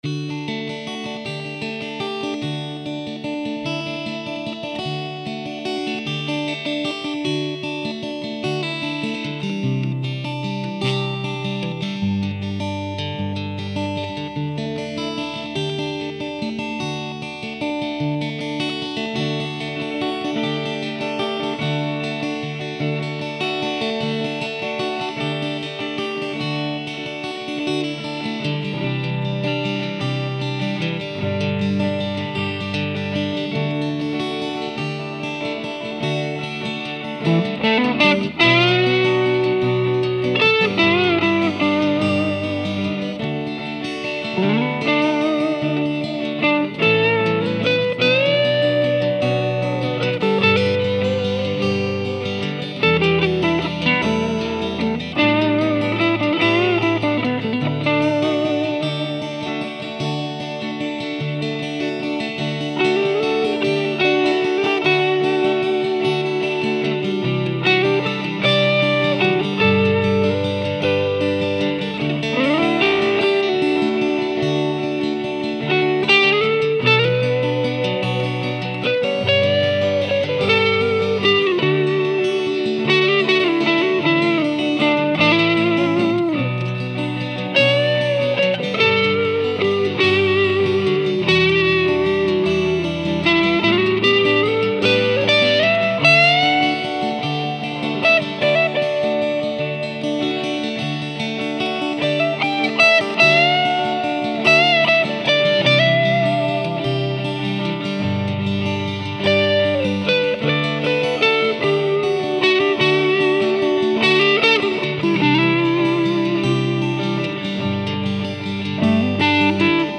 Guitars: Fender Stratacoustic, an acoustic guitar with a Strat style neck and head, equipped with a Fishman Classic IV MT (with tuner and blend controls) and Telecaster pickup
Amplifier: ARACOM VRX22 Head, VRX 1x12 Extension Cabinet equipped with a 12" Jensen P12N ALNICO speaker
Pedals: KASHA Overdrive, for the lead track
The amp was recorded with a Nady RSM-2 ribbon microphone placed approximately an inch away from the grille cloth, dead-center in relation to the speaker.
The tone coming through is simply crisp and clear. For the lead, still using the Stratacoustic, I kicked in my KASHA Overdrive to get a real nice, slightly dirty, sweet Strat tone.